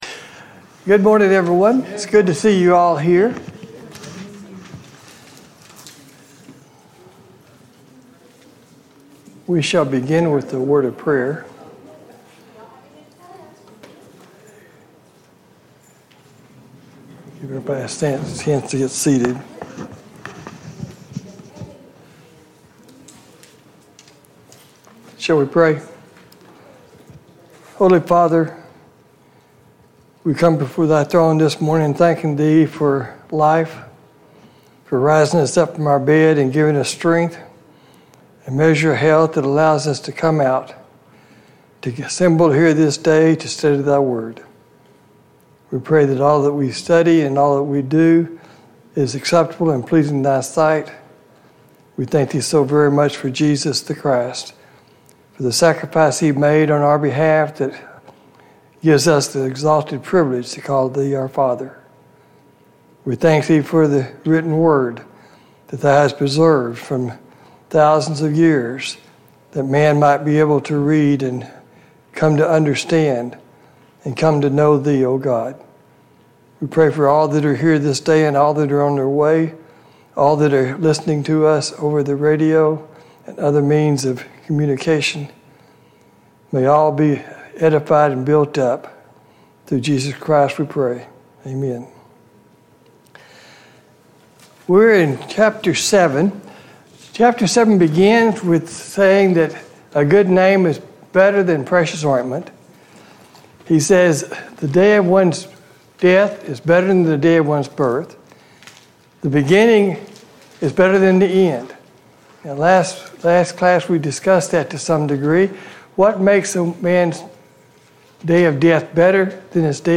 A Study of Ecclesiastes Service Type: Sunday Morning Bible Class Topics